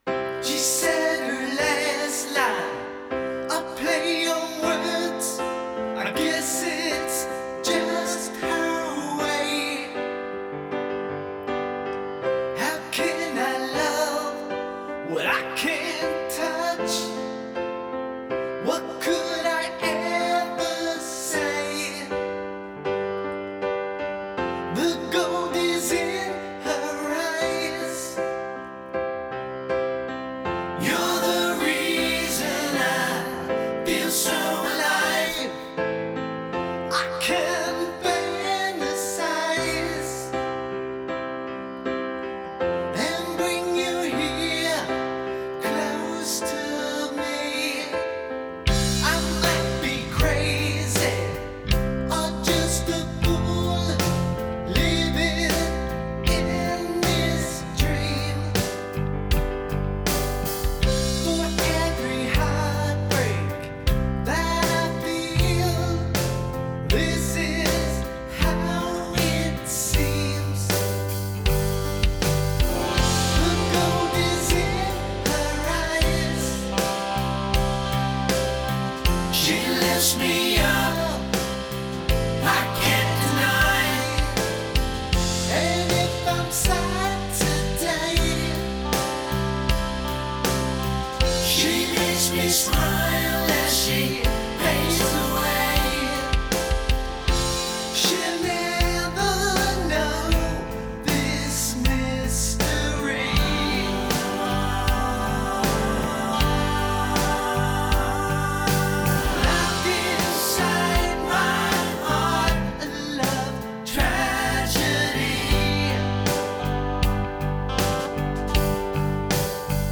backing vox & guitars